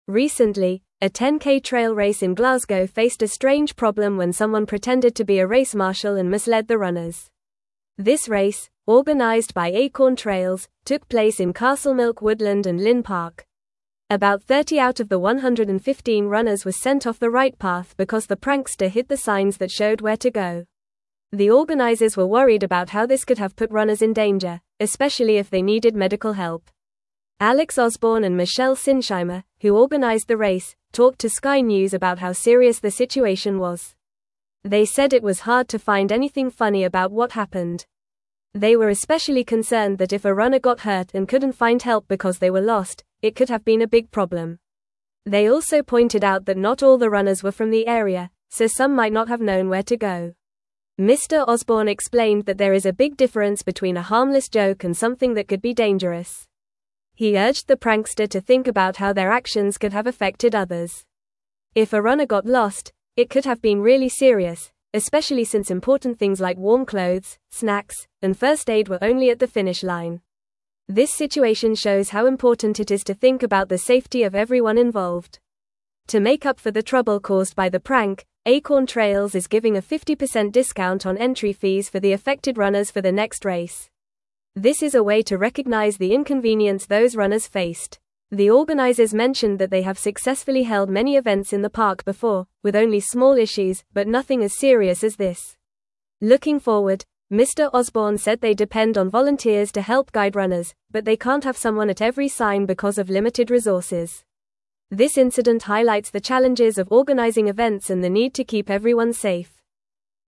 Fast
English-Newsroom-Upper-Intermediate-FAST-Reading-Prankster-Disrupts-Glasgow-10k-Trail-Race-Direction.mp3